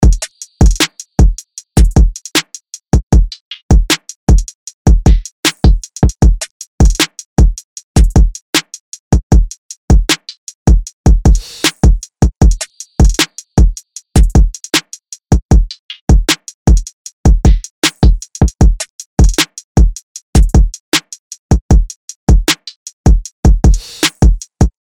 LP 095 – LOOP – HIPHOP TRAP- 155BPM